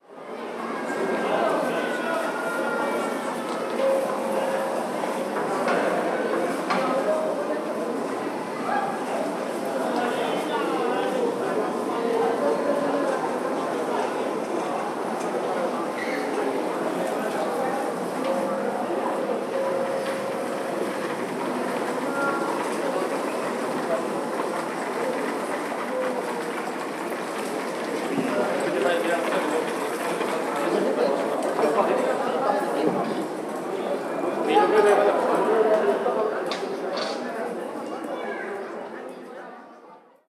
Ambiente de una plaza con gente
tránsito
terraza
barullo
murmullo
niño
Sonidos: Gente
Sonidos: Ciudad